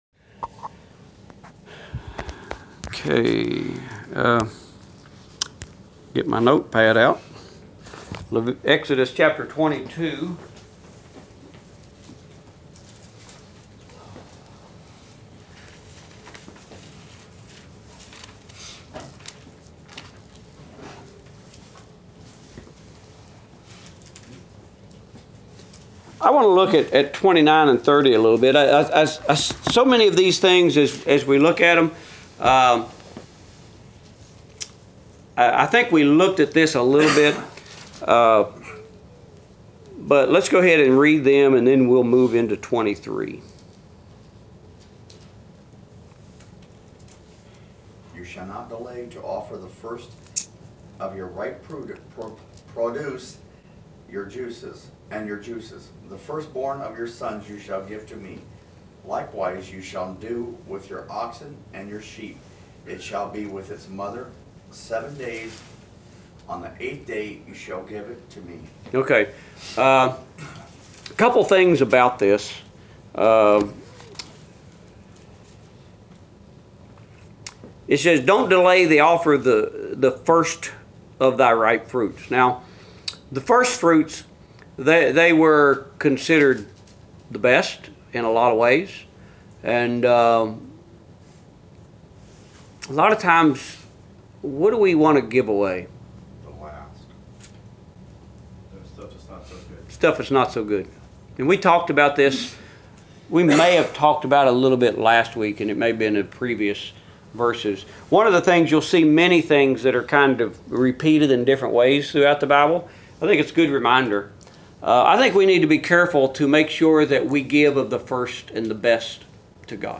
Adult Bible Class: Exodus 23